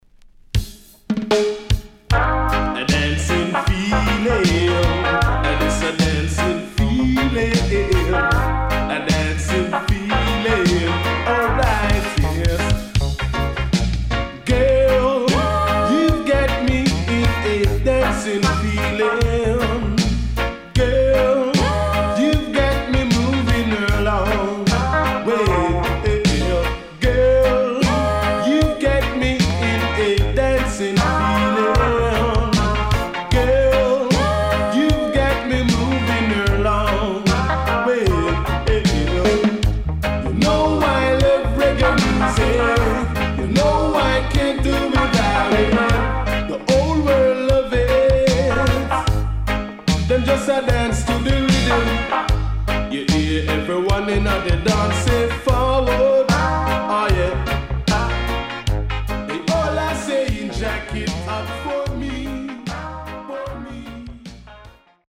Good Reggae Vocal